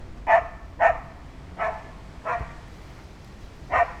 dog-dataset
puppy_0023.wav